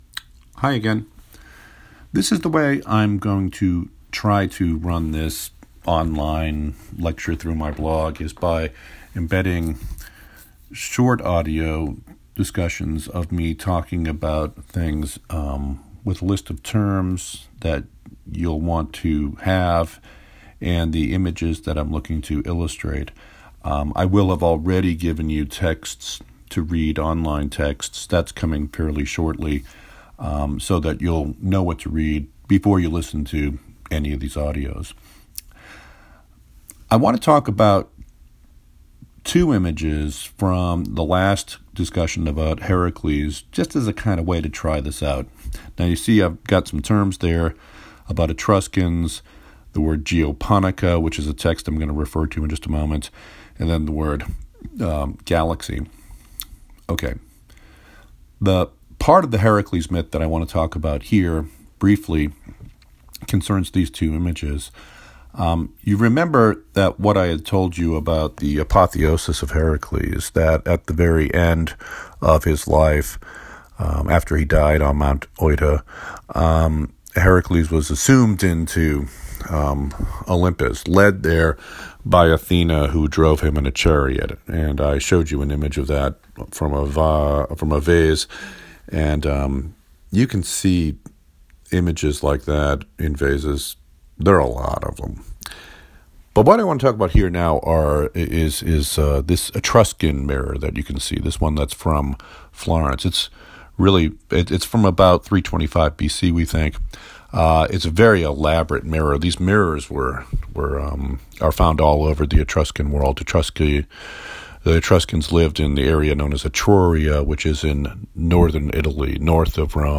Discussion of two images of Heracles and Hera